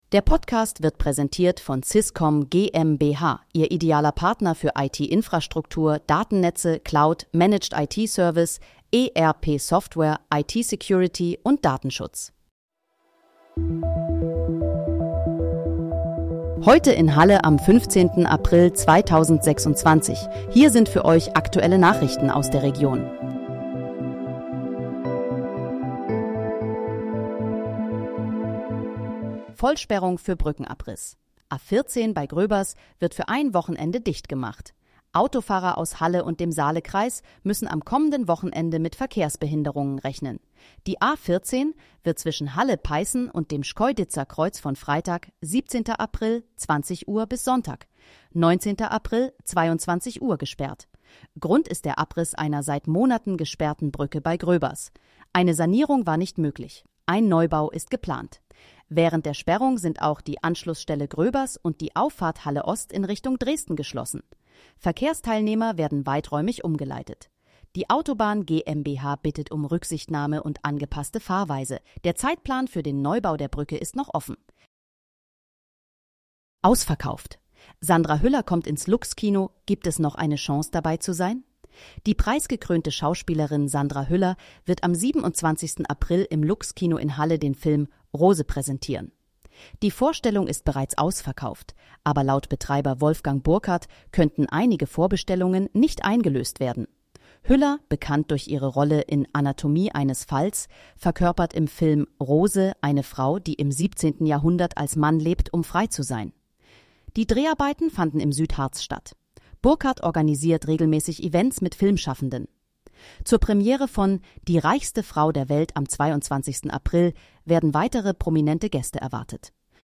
Heute in, Halle: Aktuelle Nachrichten vom 15.04.2026, erstellt mit KI-Unterstützung